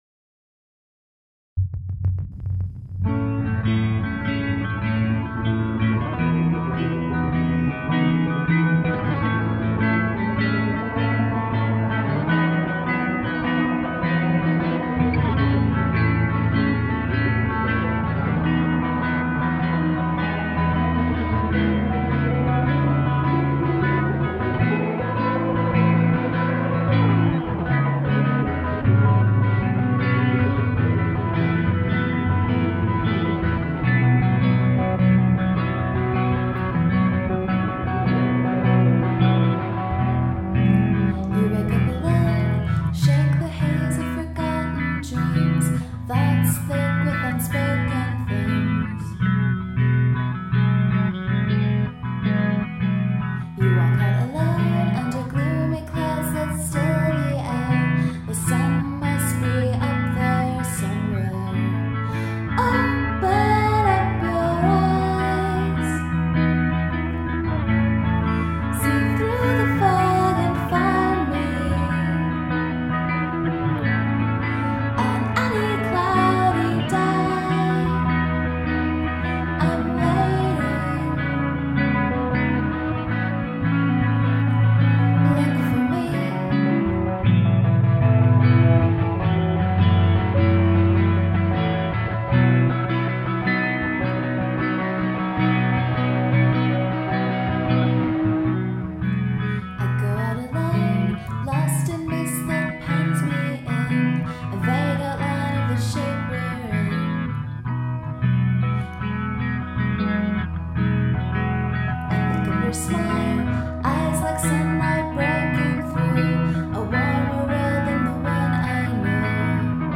this is the first recording of my nonband! it is a live studio recording. i wrote the words and melody and someone else wrote and played the guitar part. i think the words are a little ungainly in a few places, particularly the lines with "gloomy" and "mist" in them. and the line "i think of your smile" is so gross. i'm pretty happy with the chorus, i think it walks a fine cheesy line but for some reason it seems more cute than grating. i like the end a lot, although maybe the melody does not make sense in context. there's going to be a bit more of an outtro but it hasn't been written yet. i'll keep updating the mp3 when we add more to it.
uploaded a new version of the song with added guitars and some weird atmospherics.